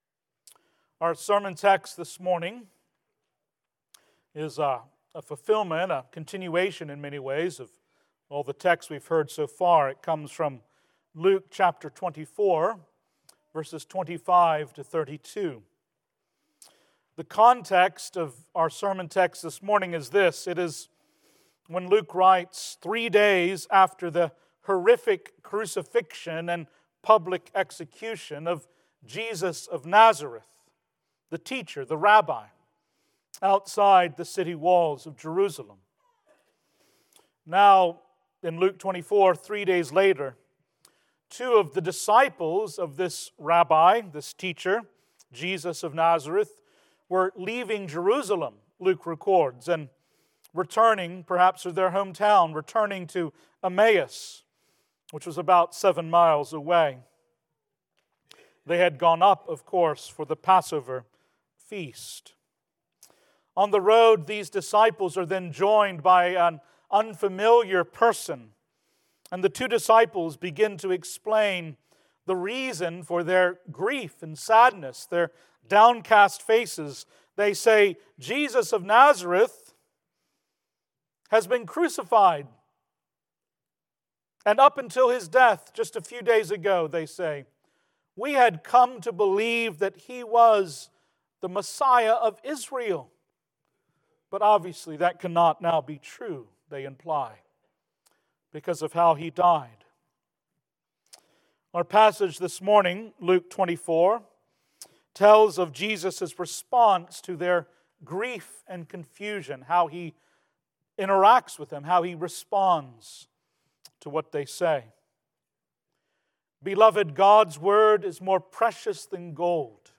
Service Type: Worship